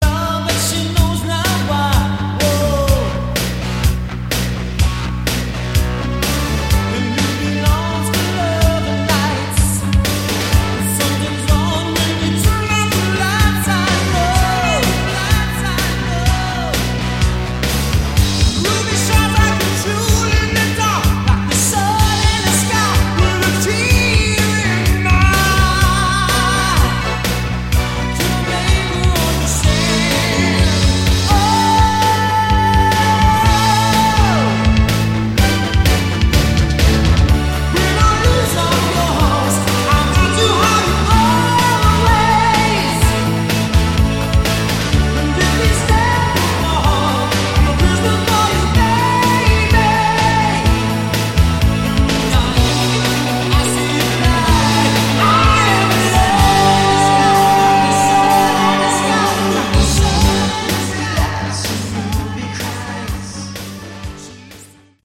Category: AOR
keyboards & synthesizers
guitar & vocals
bass & Taurus pedals